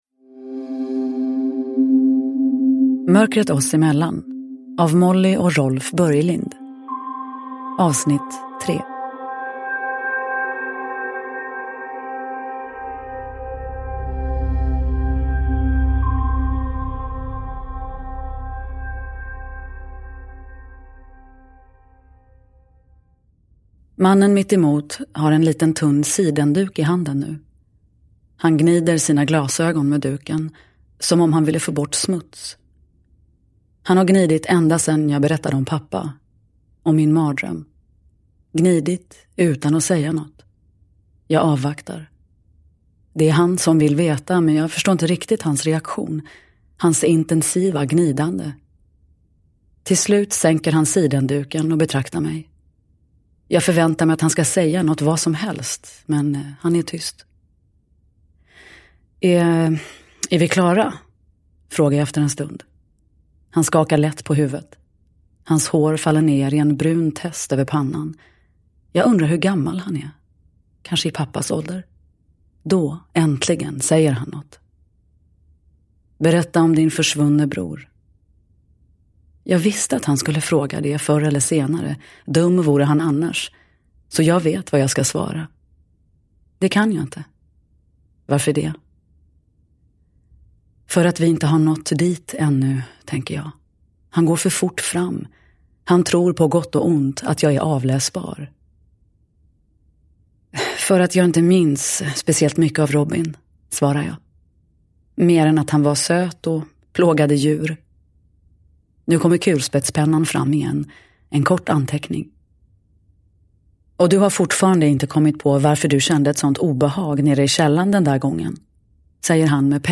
Mörkret oss emellan. 3 – Ljudbok – Laddas ner
Uppläsare: Nina Zanjani